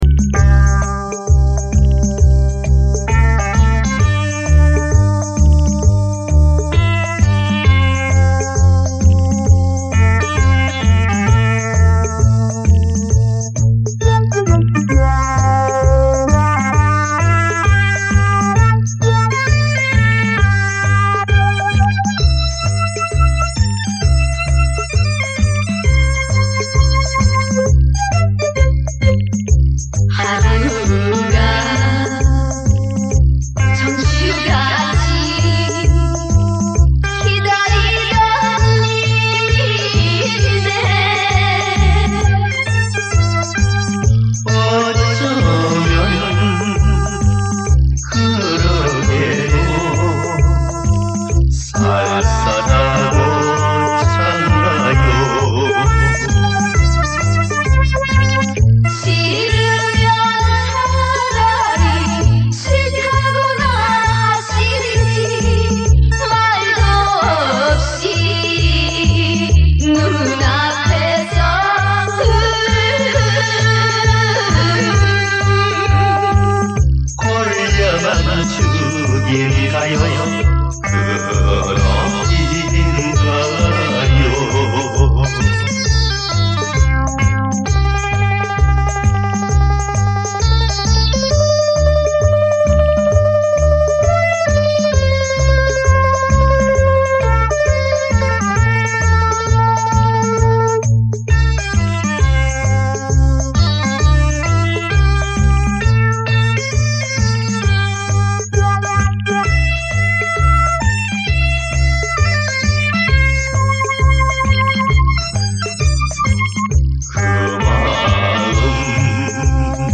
只能截取其中一首。